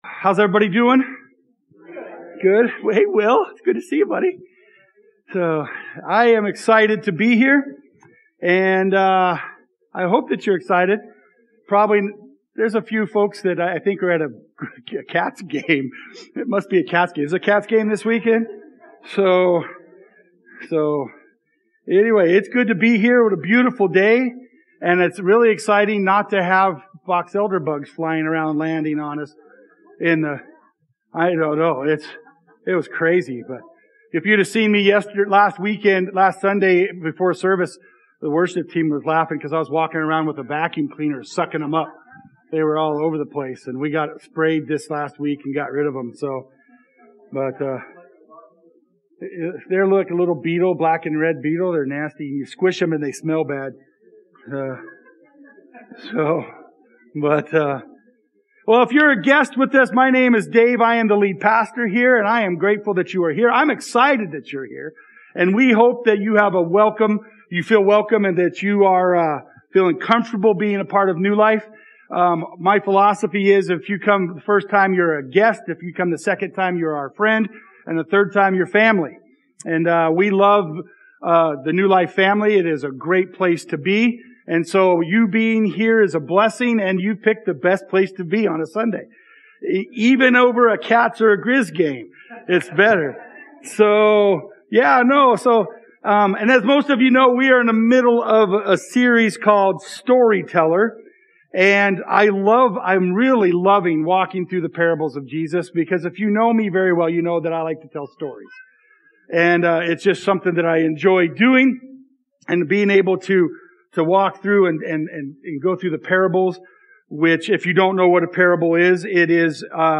Sermons | New Life Fellowship Conrad